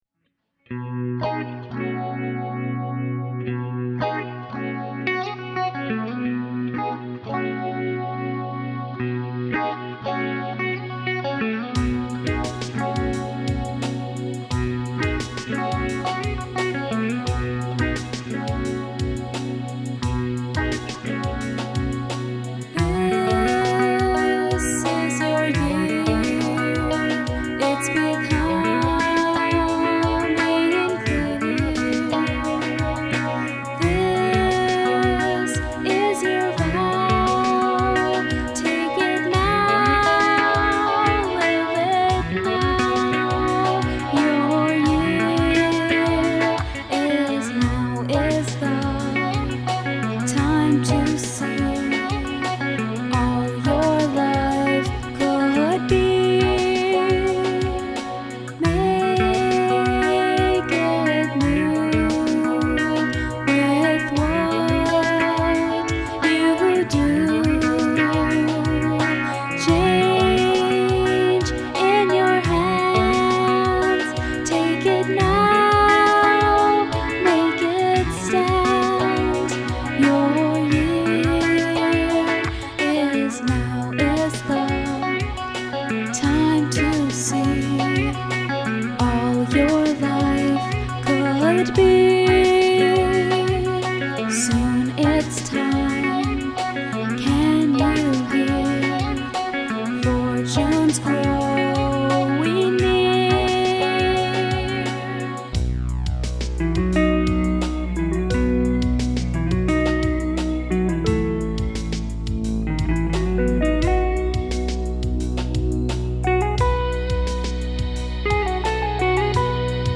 An optimistic pop ditty recorded for New Year's 2007.